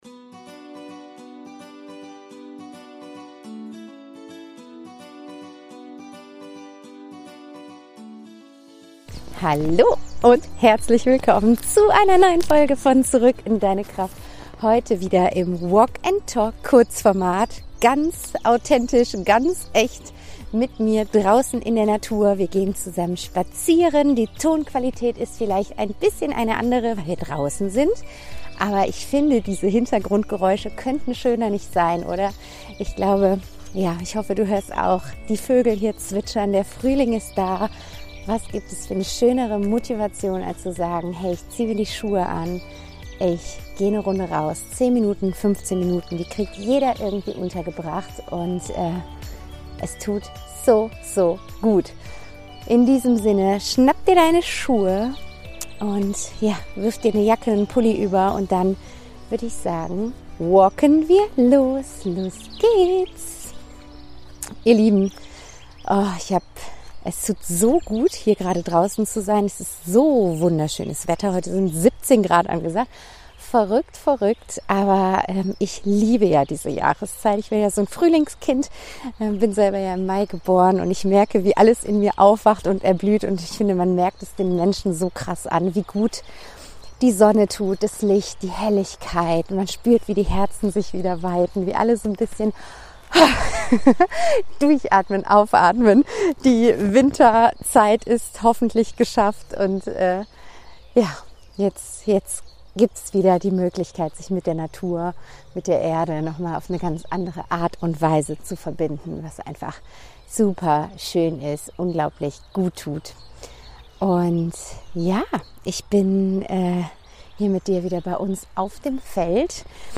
In diesen Folgen nehme ich dich mit auf einen Spaziergang. Beim Gehen teile ich spontane Gedanken, ehrliche Impulse und leise Fragen aus dem Moment heraus – unperfekt in der Tonqualität, dafür nah, authentisch und mitten aus dem Leben.